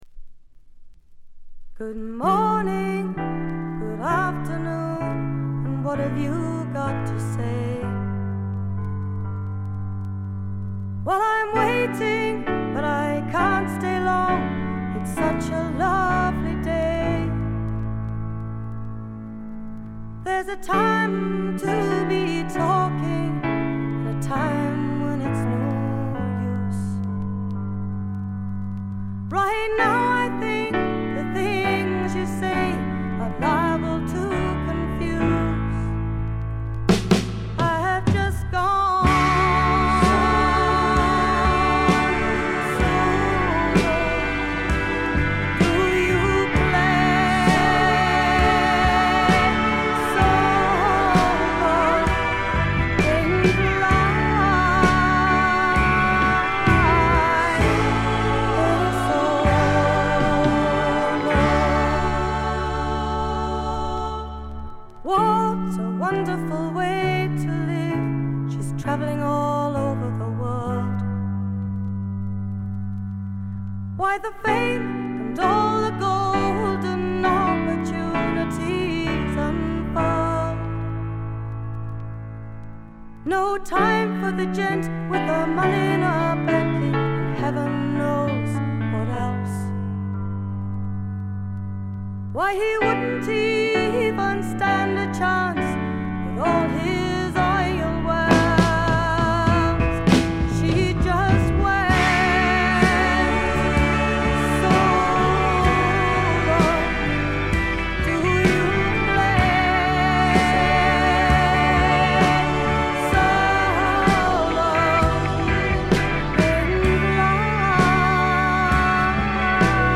軽微なチリプチ程度。
1stのようないかにもな英国フォークらしさは影を潜め、オールドタイミーなアメリカンミュージック風味が加わってきています。
試聴曲は現品からの取り込み音源です。